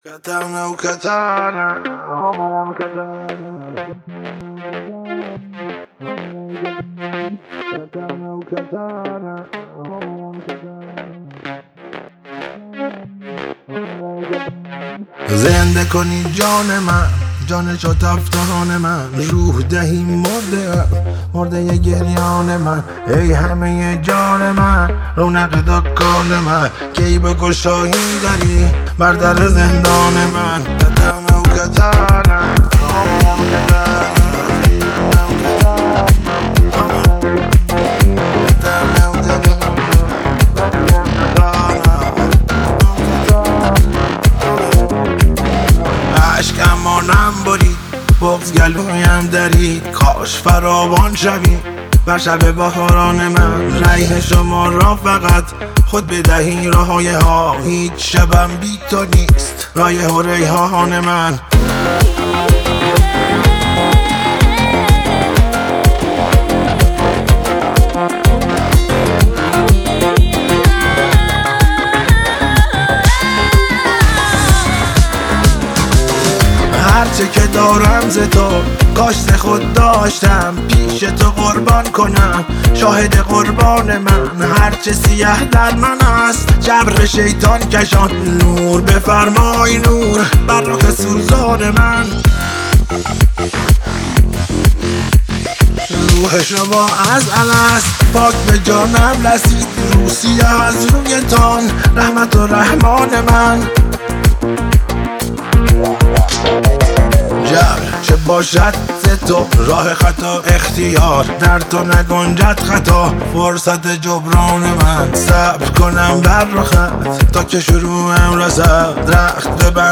موسیقی ایرانی